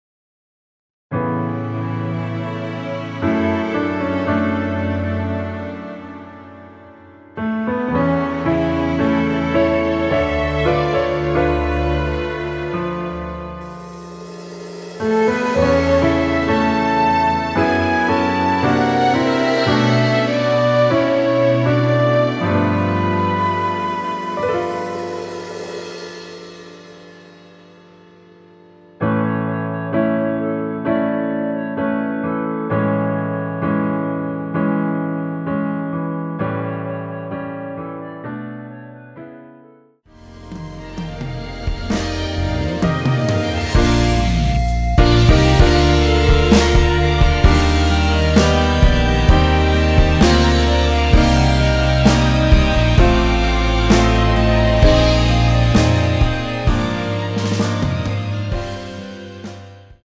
원키 멜로디 포함된 MR입니다.
멜로디 MR이란
앞부분30초, 뒷부분30초씩 편집해서 올려 드리고 있습니다.
중간에 음이 끈어지고 다시 나오는 이유는